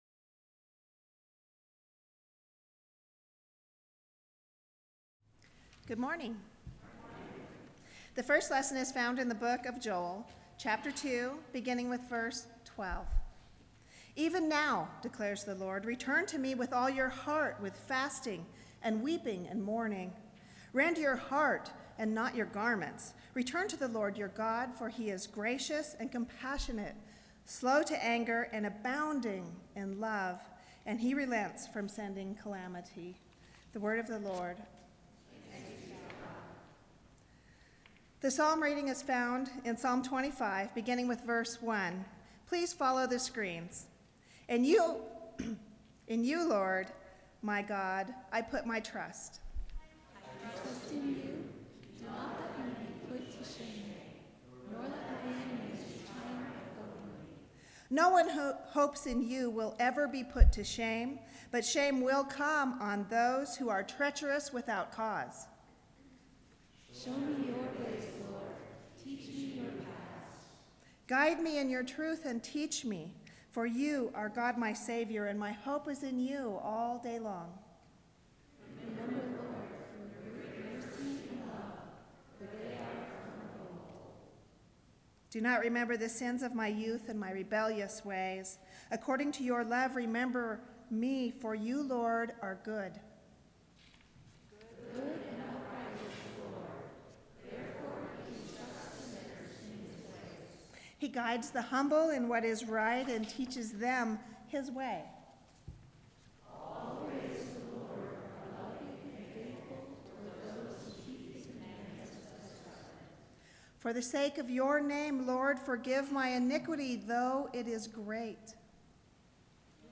Audio sermon